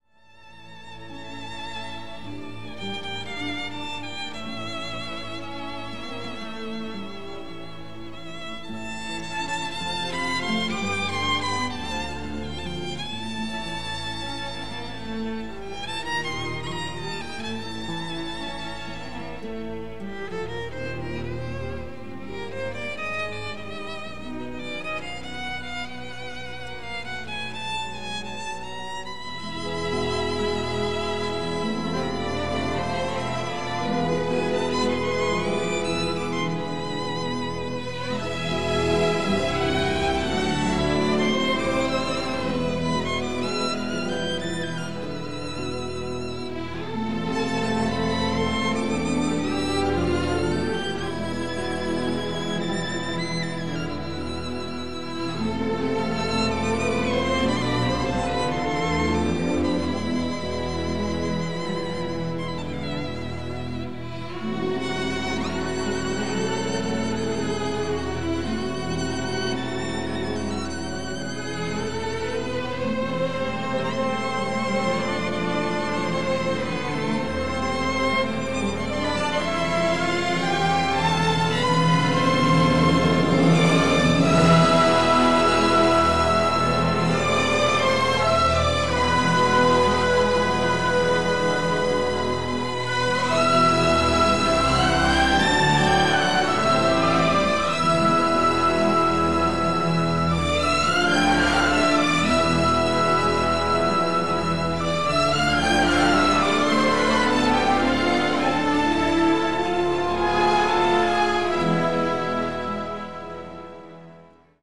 Original track music: